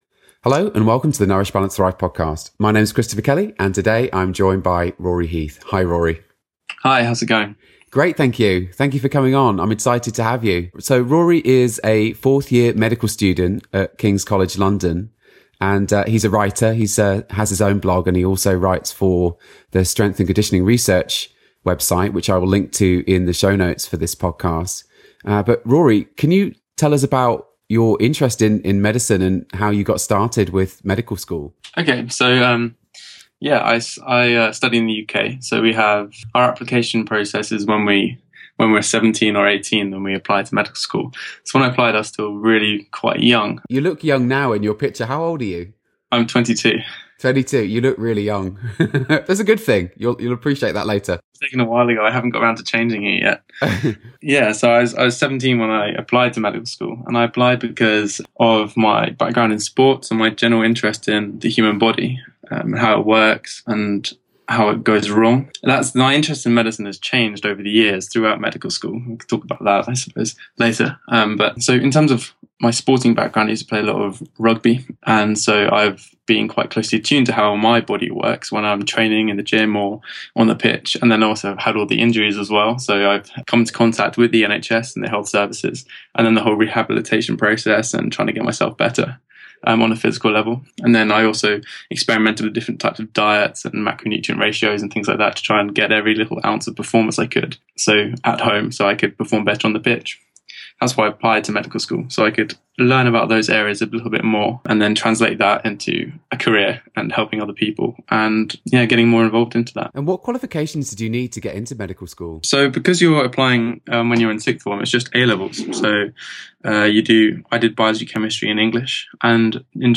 An Interview with a 4th Year Medical Student